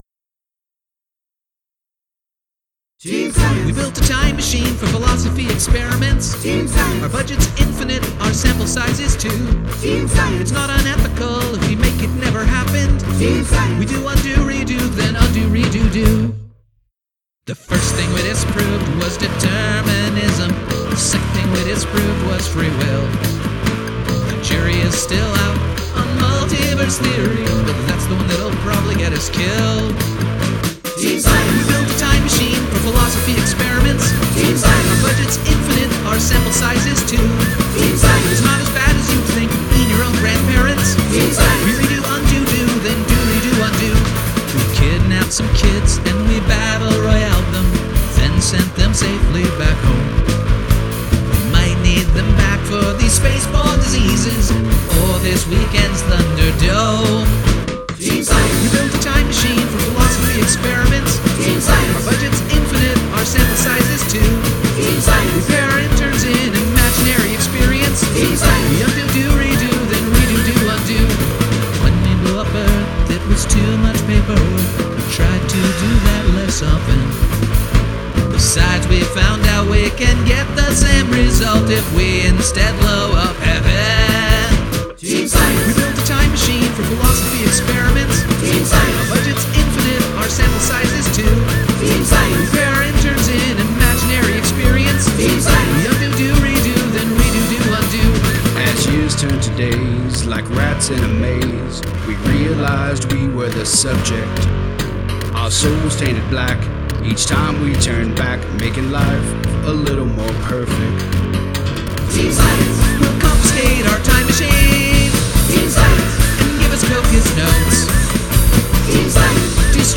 Here's my latest: Latest mix: mastered Unmastered Mix 2 Mix 1 I'm really trying to refine my mastering chain, so feedback on that is especially helpful.